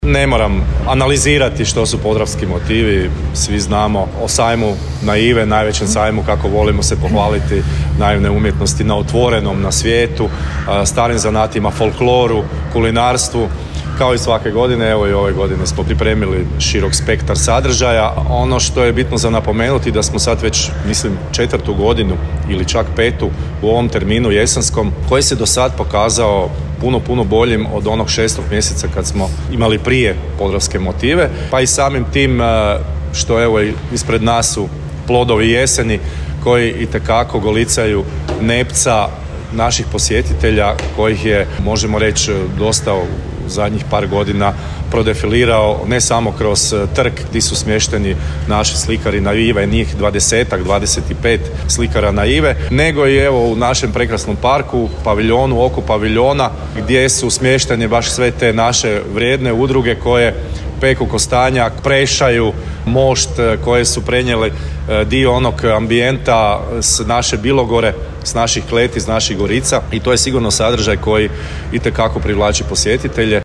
na konferenciji za medije